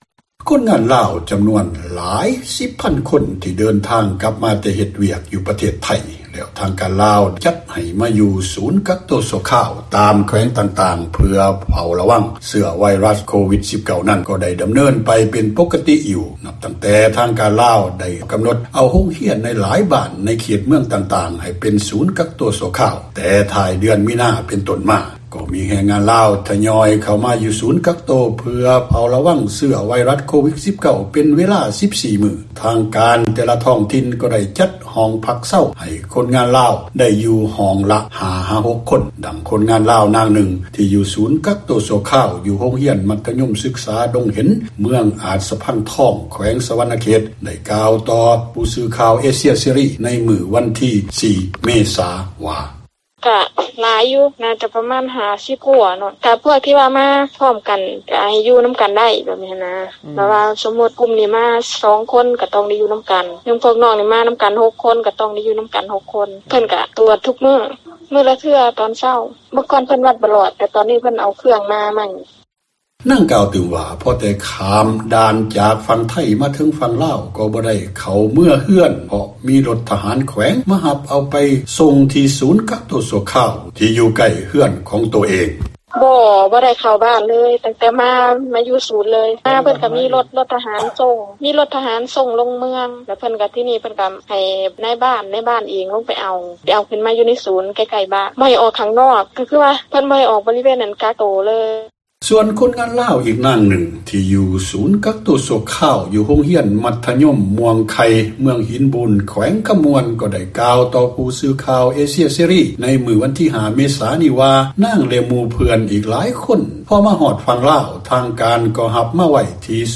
ຄົນງານລາວໃນສູນກັກໂຕ ຢູ່ເປັນ ປົກກະຕິ – ຂ່າວລາວ ວິທຍຸເອເຊັຽເສຣີ ພາສາລາວ